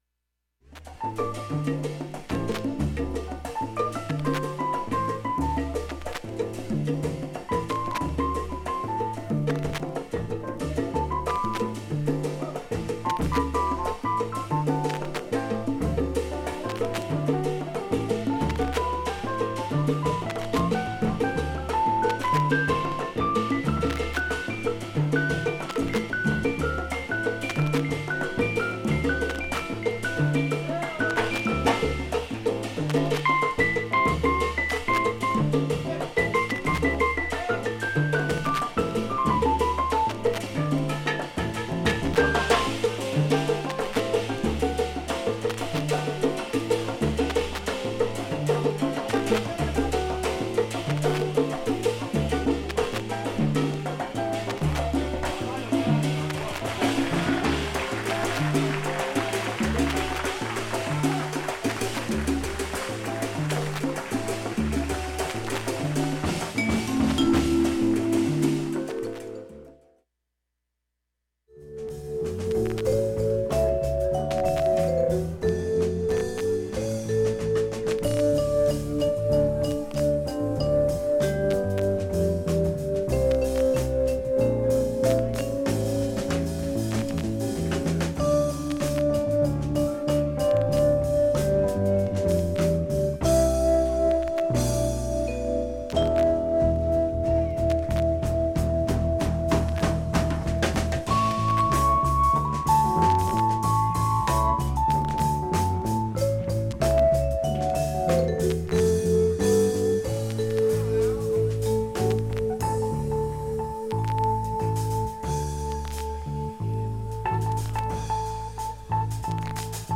ようなもので周回シャリ音出ます、
音自体あまりストレスは無い感じです
シャッシャッと少し大きめです。
シャッシャッと周回でなります。
ほか７回までのかすかなプツが１箇所